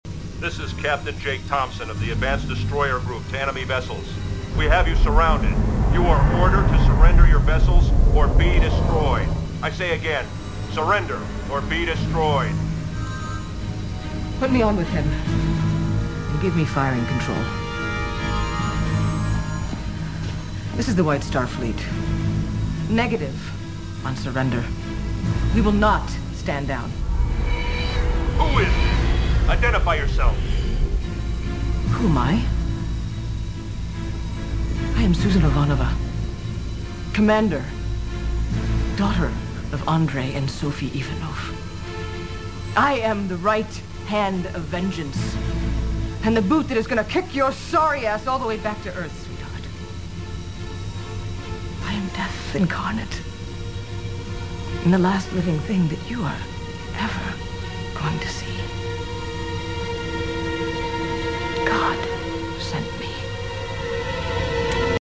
Ca vaut le coup d'attendre la saison 4 rien que pour la tirade héroïque de la belle et froide Susan !